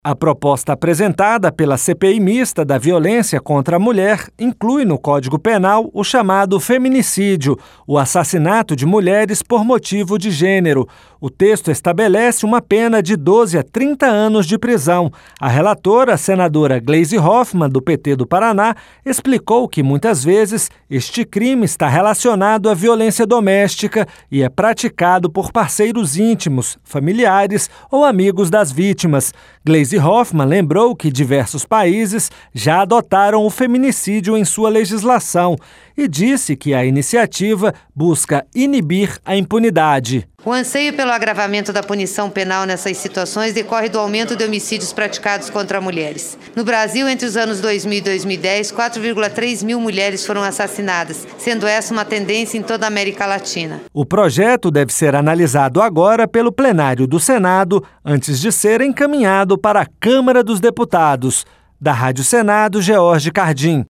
RadioAgência